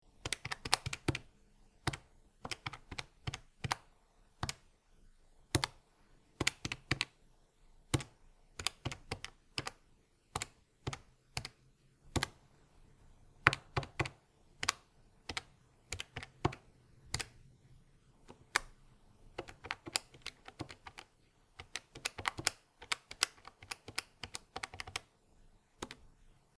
Звук инженерного калькулятора на столе